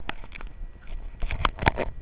If it helps, here is a sample file (recorded w/ my portable player)